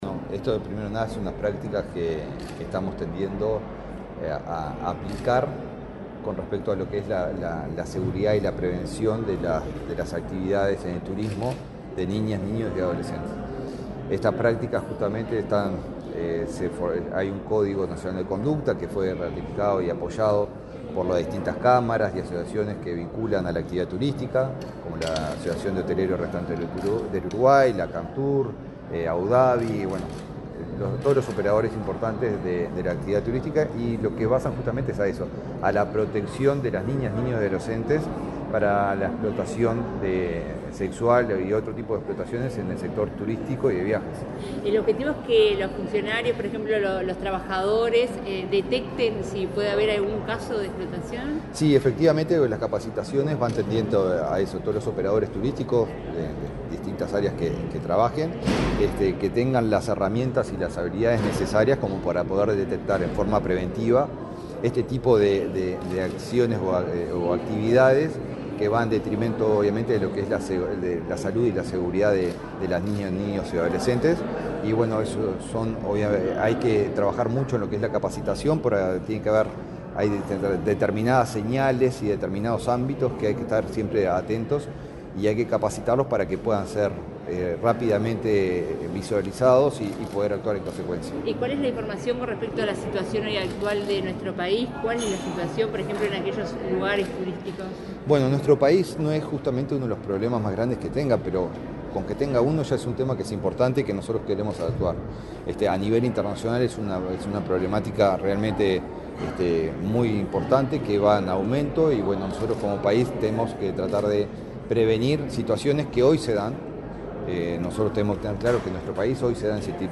Declaraciones del ministro de Turismo, Eduardo Sanguinetti
Este martes 24 en el Palacio Legislativo, el titular del Ministerio de Turismo, Eduardo Sanguinetti, dialogó con la prensa, luego de participar en la